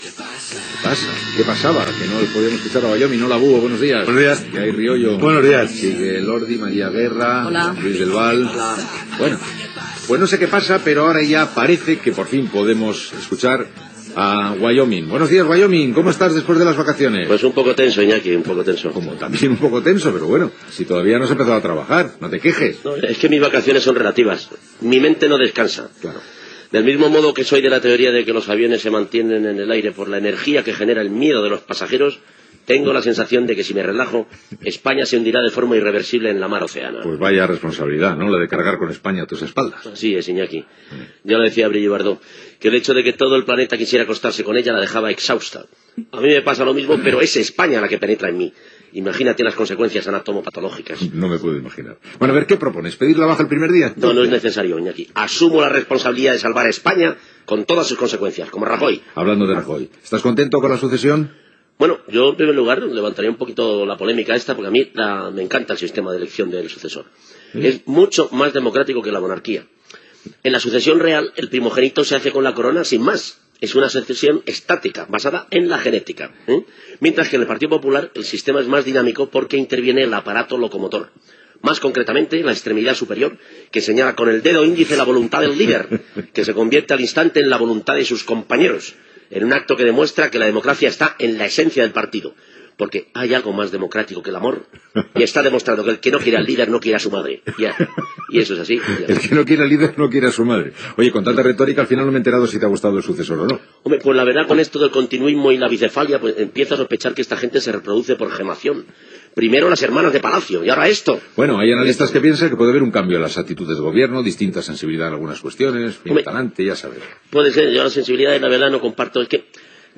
Salutació a l'equip i el Gran Wyoming (José Miguel Monzón) comenta la successió en la candidatura del Partido Popular a la presidència del Govern espanyol, amb Mariano Rajoy al capdevant.
Info-entreteniment